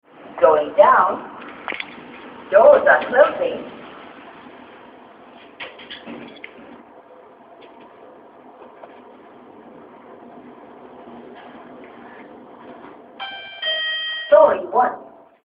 Ah, der Lift ist übrigens recht gesprächig.
plappert er vor sich hin.
lift.mp3